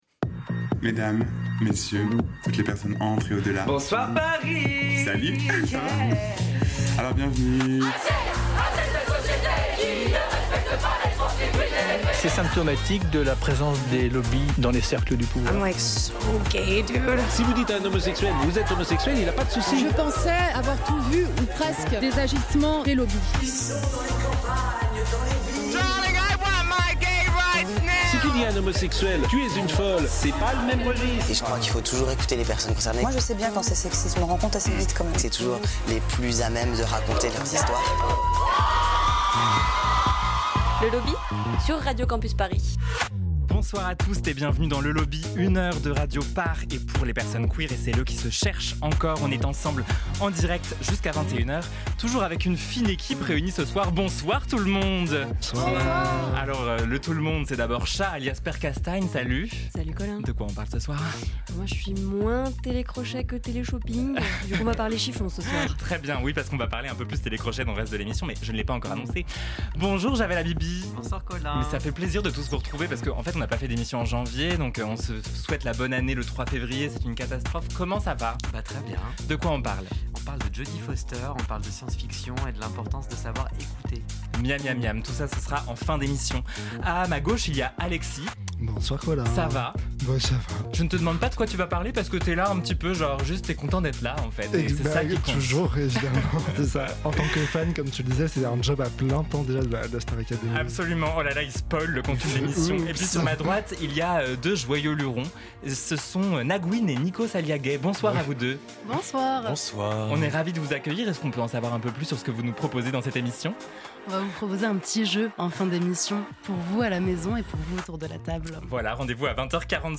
est en studio avec nous ce mois-ci pour un débrief queer de la dernière saison de la Star Academy !!!!!